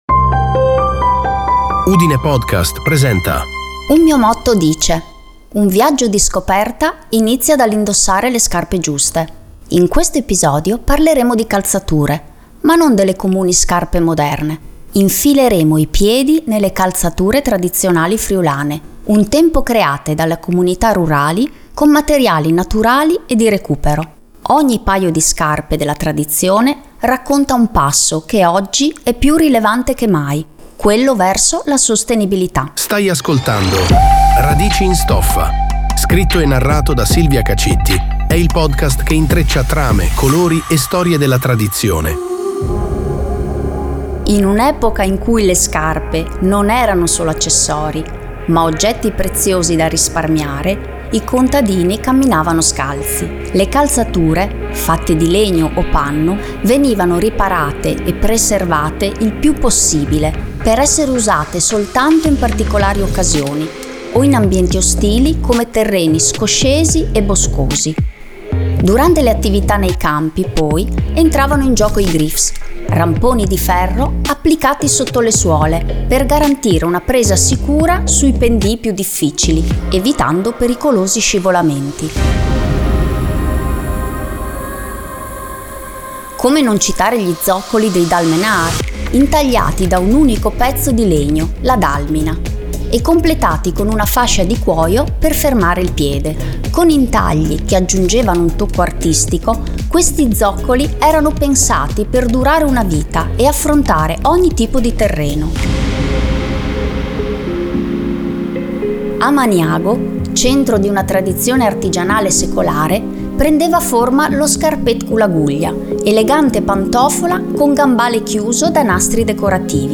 Music by Rockot – BackgroundMusicForVideo from Pixabay